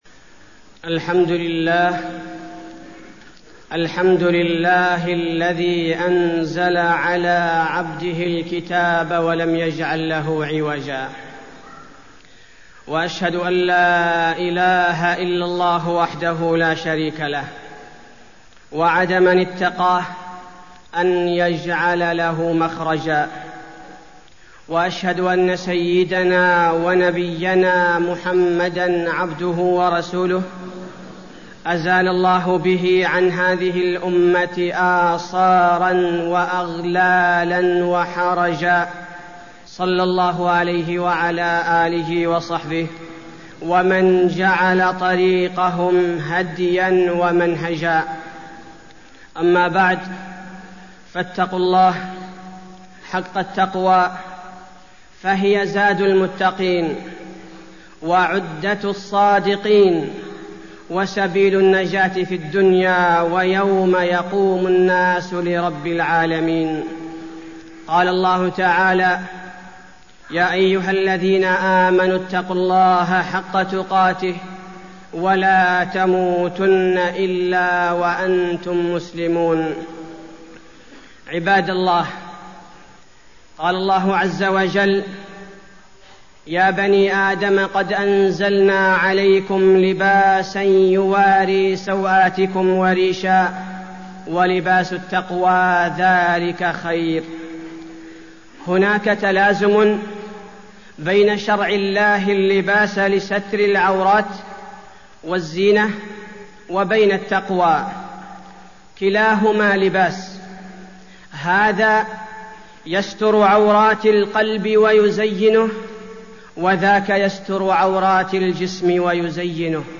تاريخ النشر ١٦ شوال ١٤١٨ هـ المكان: المسجد النبوي الشيخ: فضيلة الشيخ عبدالباري الثبيتي فضيلة الشيخ عبدالباري الثبيتي اللباس الإسلامي